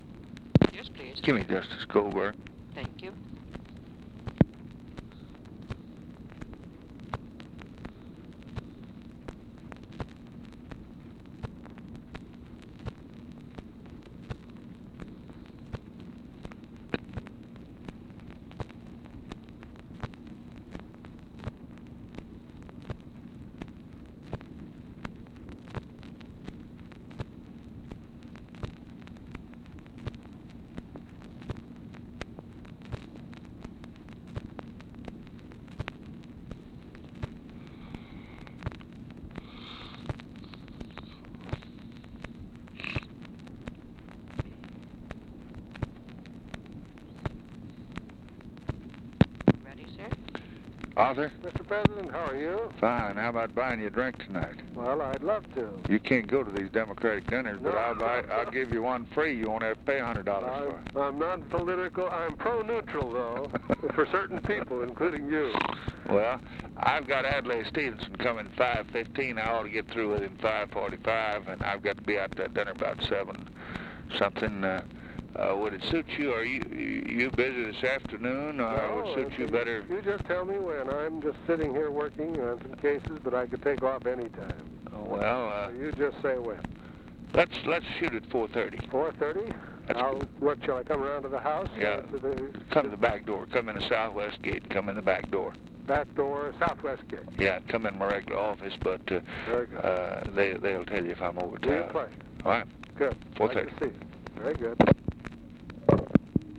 Conversation with ARTHUR GOLDBERG, March 19, 1964
Secret White House Tapes